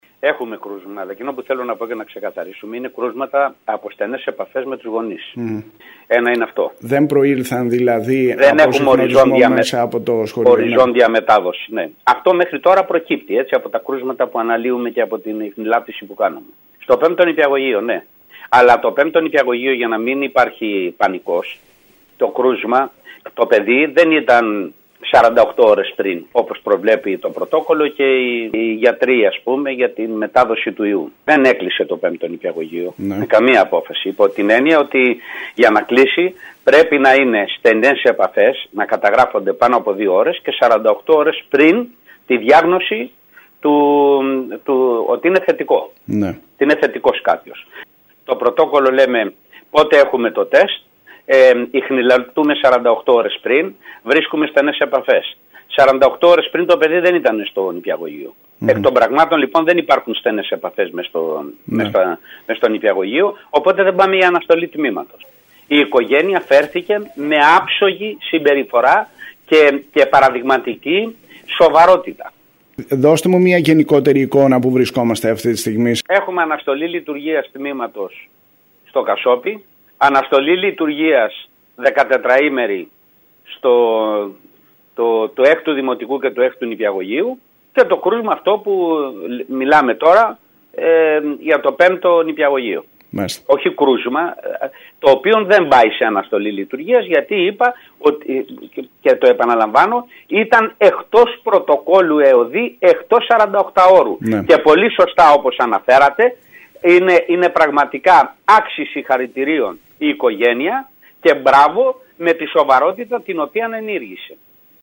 Την ανάγκη διενέργειας καθολικών rapid test στις σχολικές κοινότητες όπου διαπιστώνεται κρούσμα επισημαίνει ο Διευθυντής Πρωτοβάθμιας εκπαίδευσης Κέρκυρας, Χρήστος Άνθης.